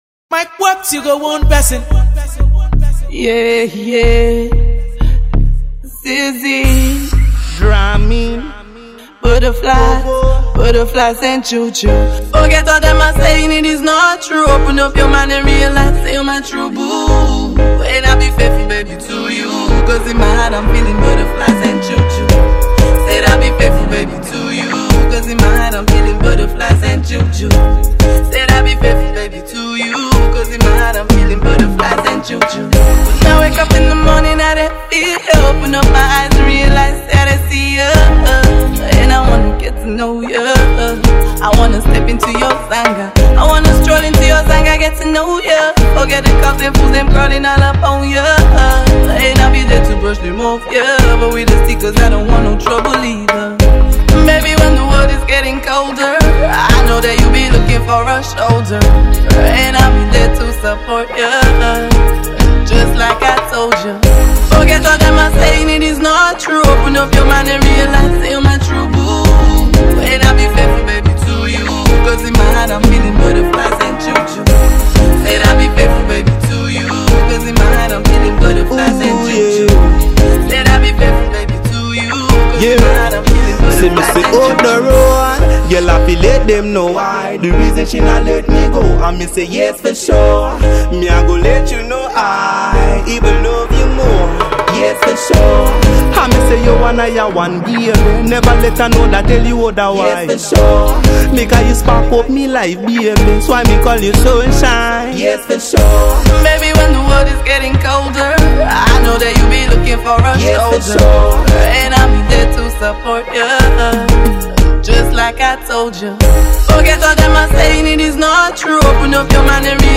Reggae/Soul singer/songwriter